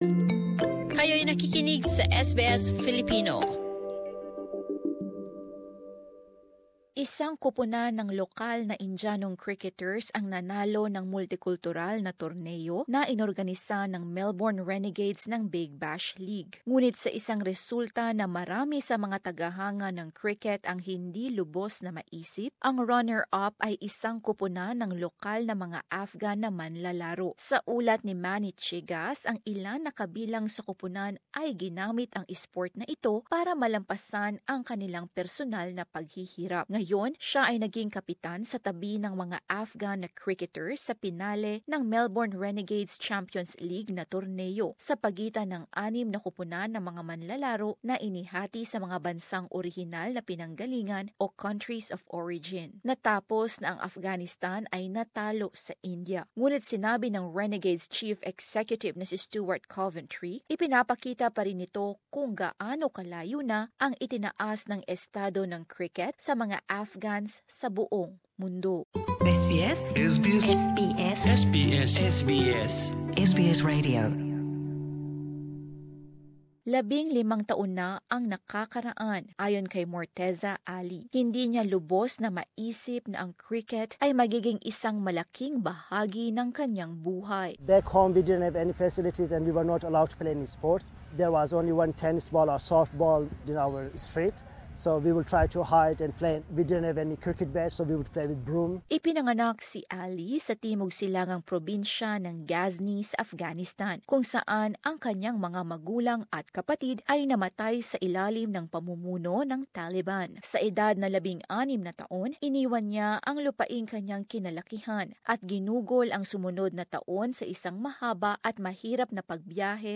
As this report shows, some of those on the team have used the sport to overcome huge personal struggles.